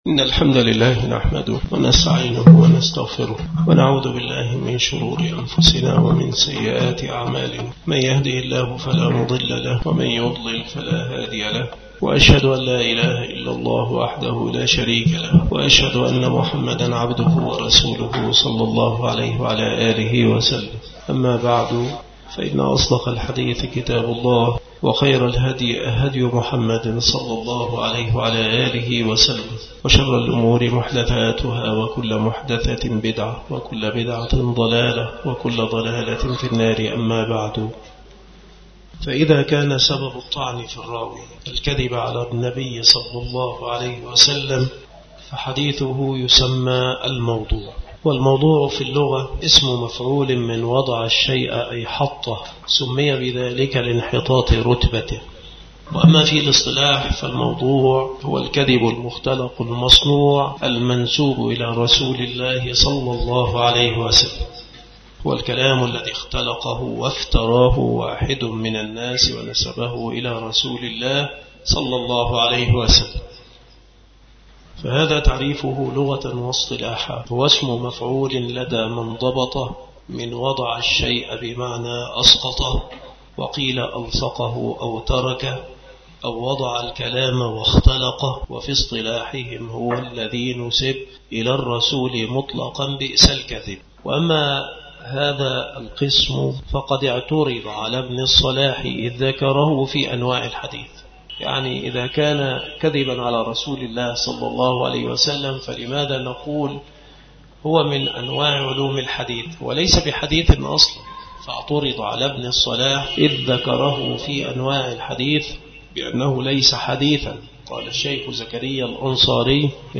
مكان إلقاء هذه المحاضرة بالمسجد الشرقي بسبك الأحد - أشمون - محافظة المنوفية - مصر عناصر المحاضرة : تعريف الموضوع لغة واصطلاحًا. حكم رواية الموضوع. طرق الوضع في الحديث. كيف تعرف الحديث الموضوع؟ الأسباب التي دعت الكذابين إلى وضع الحديث.